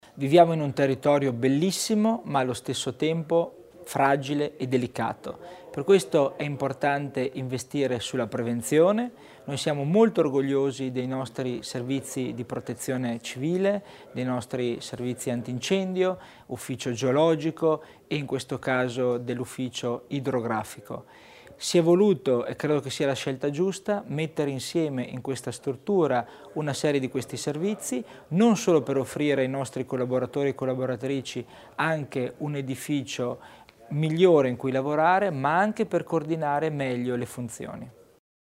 Il Vicepresidente Christian Tommasini evidenzia il valore del nuovo Ufficio Idrografico